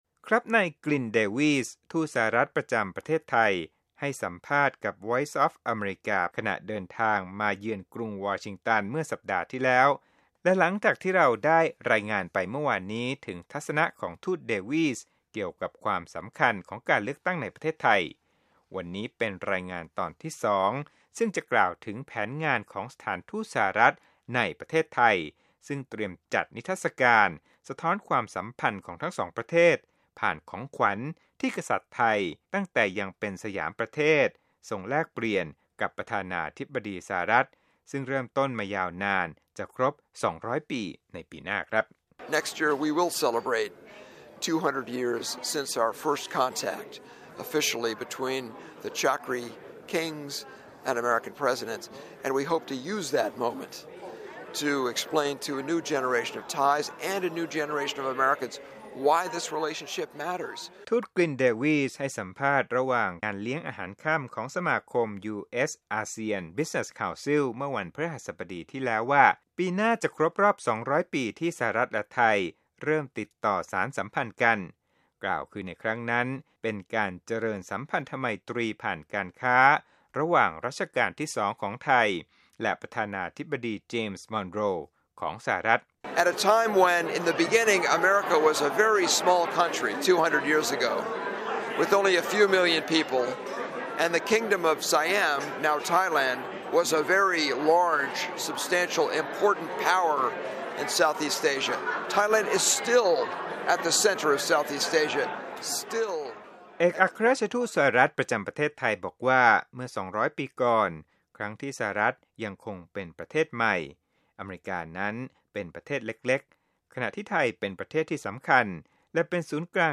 สัมภาษณ์พิเศษ: ทูตสหรัฐฯ ประจำประเทศไทย “กลิน เดวี่ส์” ตอนที่ 2